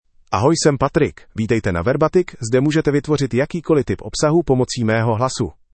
Patrick — Male Czech (Czech Republic) AI Voice | TTS, Voice Cloning & Video | Verbatik AI
PatrickMale Czech AI voice
Patrick is a male AI voice for Czech (Czech Republic).
Voice sample
Listen to Patrick's male Czech voice.
Patrick delivers clear pronunciation with authentic Czech Republic Czech intonation, making your content sound professionally produced.